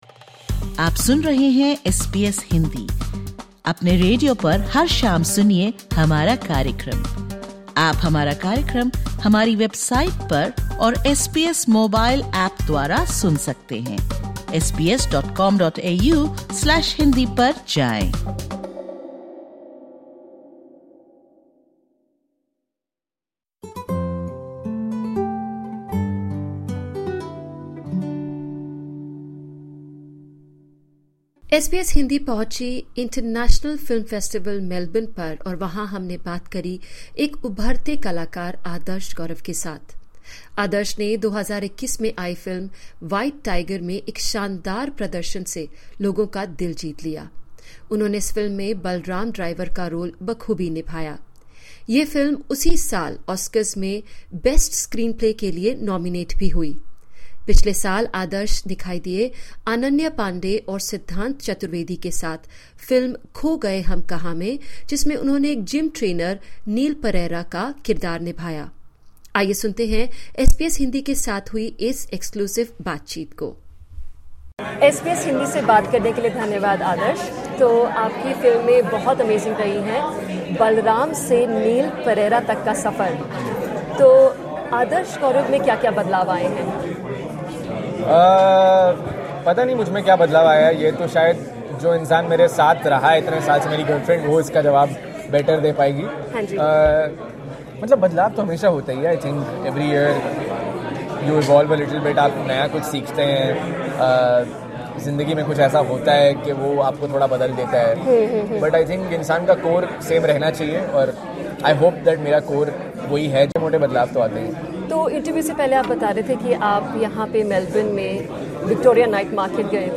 In an interview with SBS Hindi, Adarsh Gourav, acclaimed for his role in 'The White Tiger,' talks about his trip to Melbourne's Victoria Night Market, his aspiration to scuba dive at the Great Barrier Reef and his forthcoming Hollywood venture.